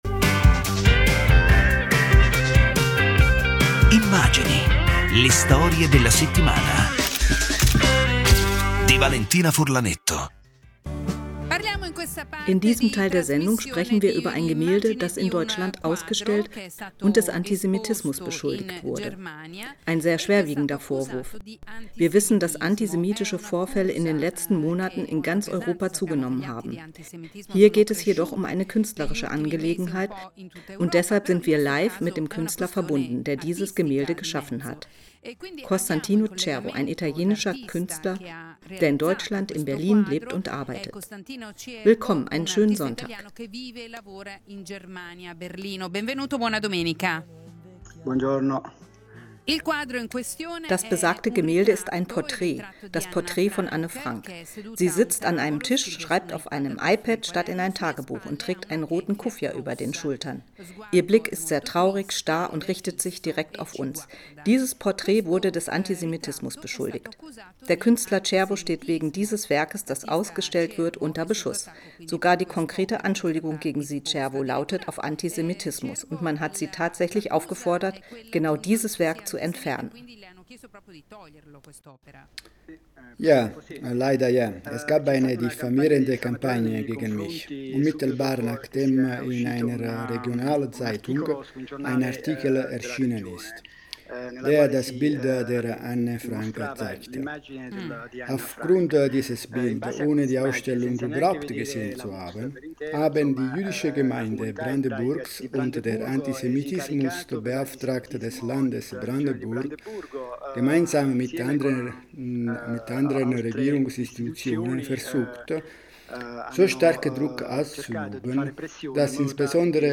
Originalfassung auf Italienisch, deutsche Synchronfassung